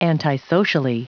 Prononciation du mot antisocially en anglais (fichier audio)
Prononciation du mot : antisocially